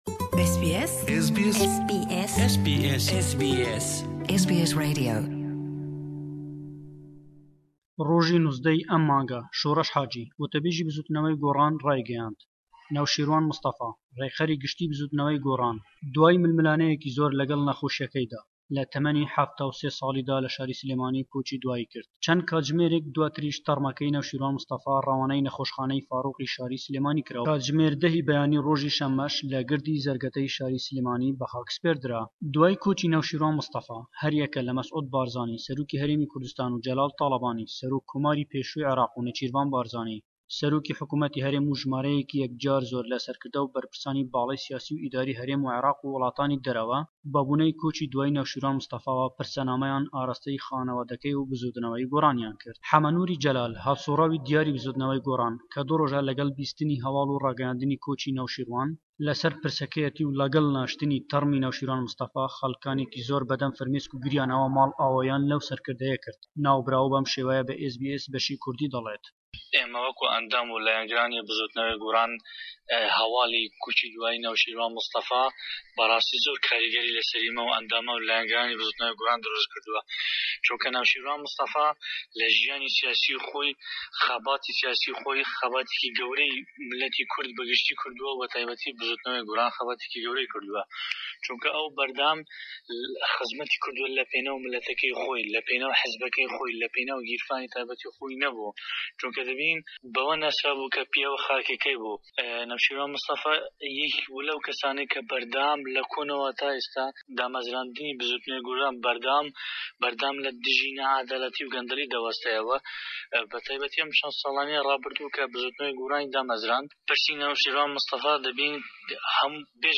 Raportêkî taybet le Hewlêre we, derbareye ew peyamaney matemînî û rêzlênan le layen siyasetmedaranî Kurde we bo koçî diwayî Rêkxerî Gishtî Bizûtnewey Gorran, kak Newshîrwan Mistefa.